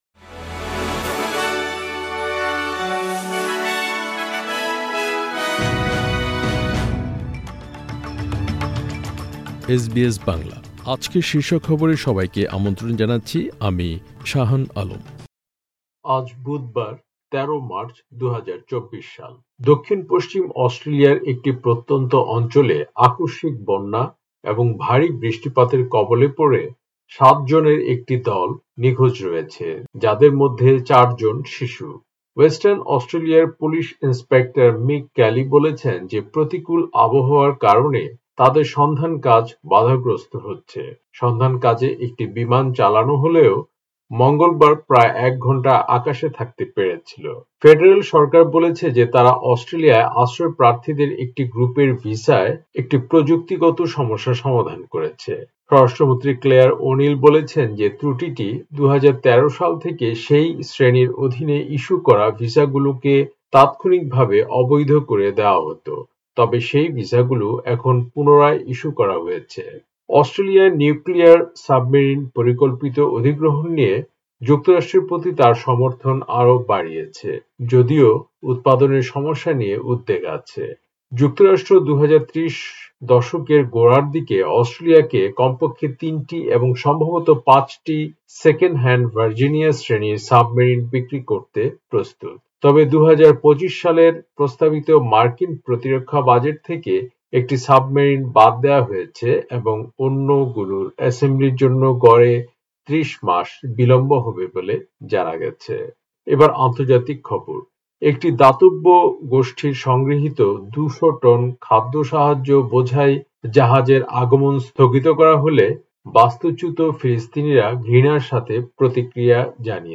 এসবিএস বাংলা শীর্ষ খবর: ১৩ মার্চ, ২০২৪